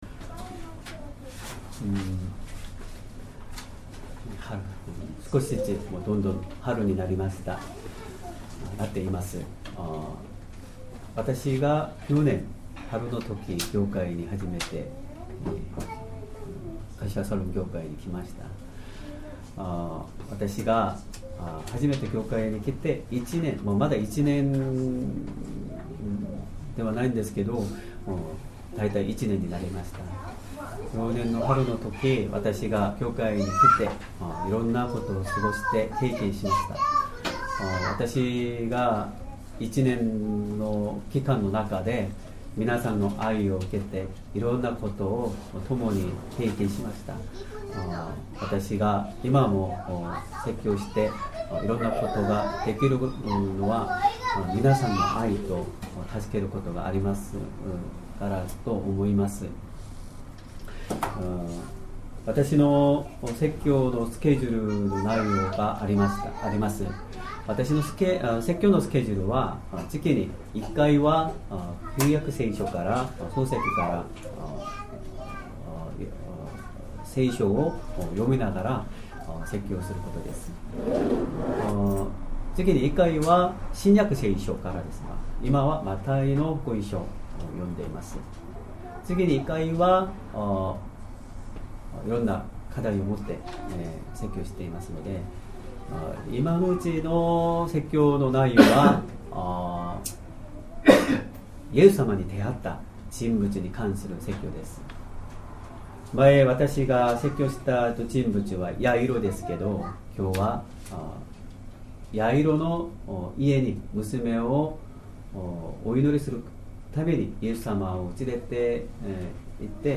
Sermon
2023年 2月18日 主日礼拝 説教 主の衣に触れた信仰 マルコの福音書5章22～43節 5:22 すると、会堂司の一人でヤイロという人が来て、イエスを見るとその足もとにひれ伏して、 5:23 こう懇願した。